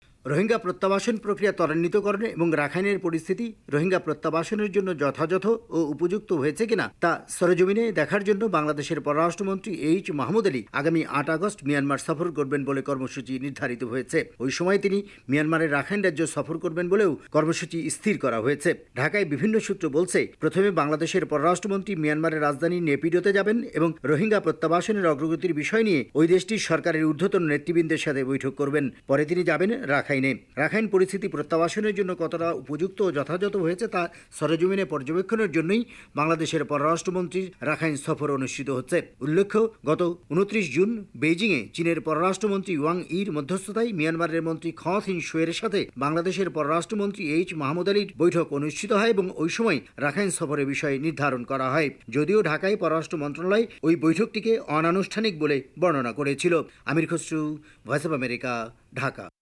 ঢাকা থেকে বিস্তারিত জানাচ্ছেন